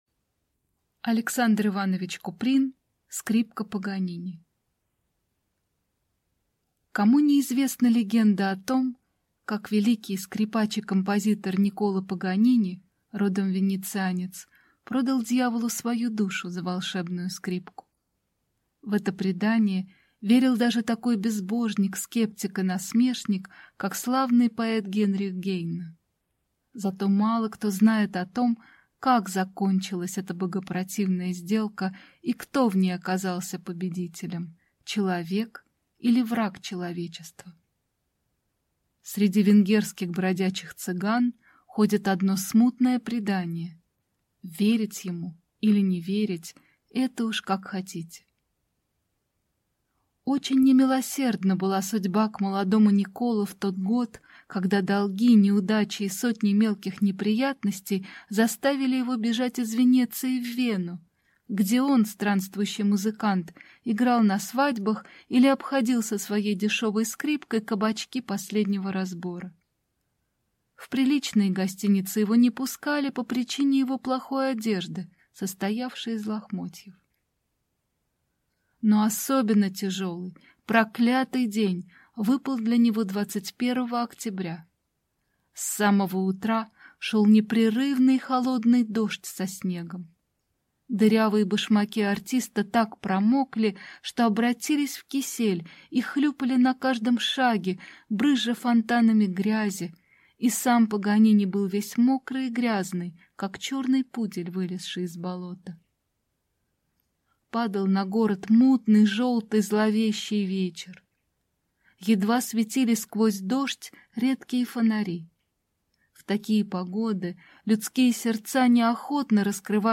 Аудиокнига Скрипка Паганини | Библиотека аудиокниг